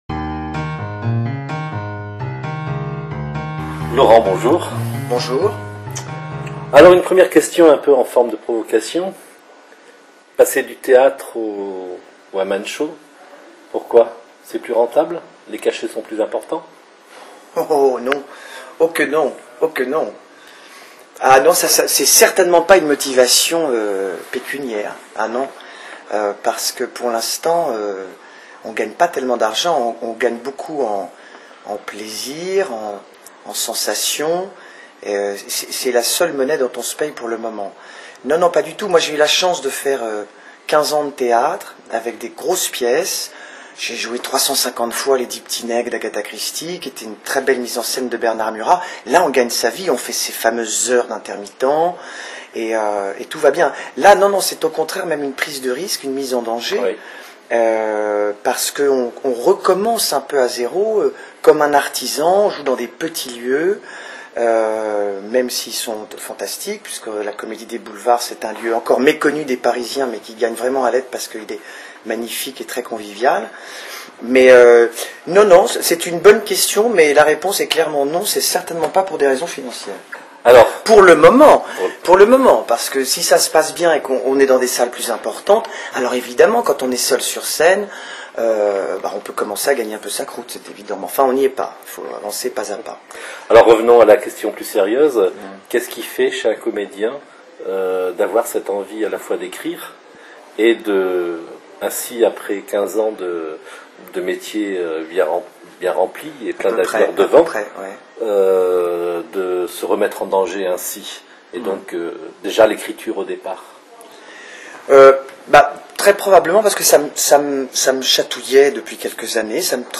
Installé confortablement, nous entamons notre entretien dans la salle, baignant dans l'atmosphère laborieuse mais discrète des préparatifs du spectacle de début de soirée.